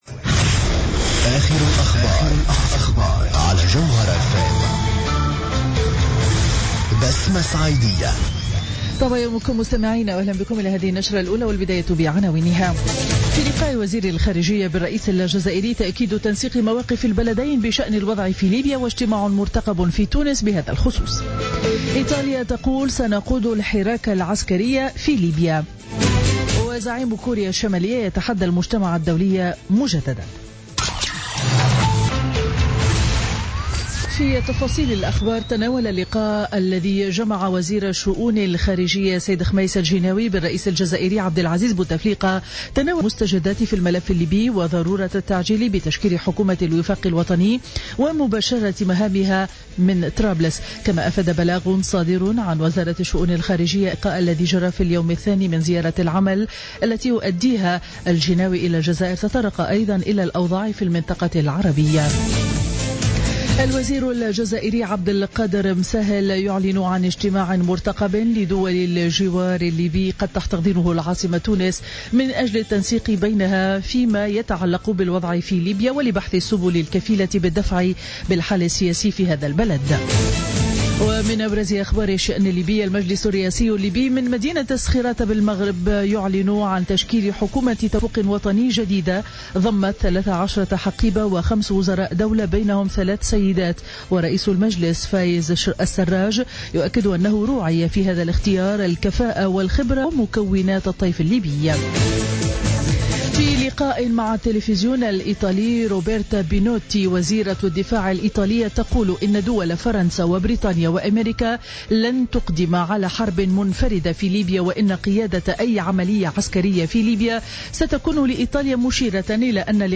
نشرة أخبار السابعة صباحا ليوم الاثنين 15 فيفري 2016